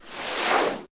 laser2.ogg